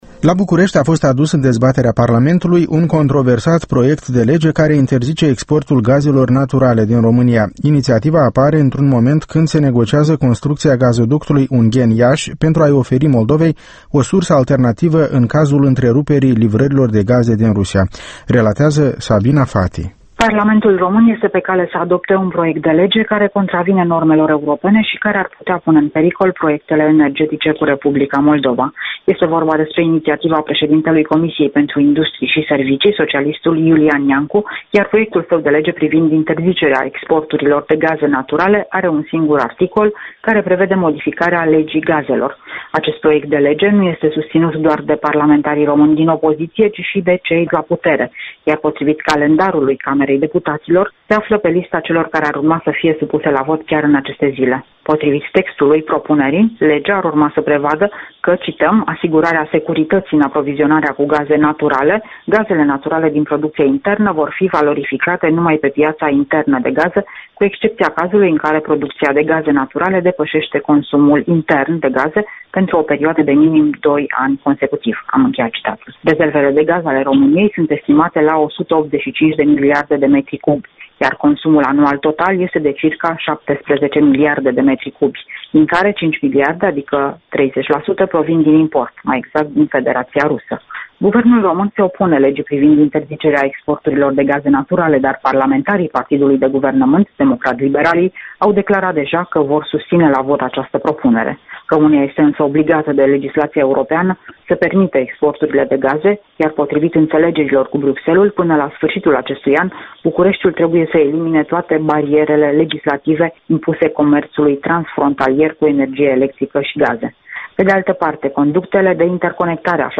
Corespondenţa zilei de la Bucureşti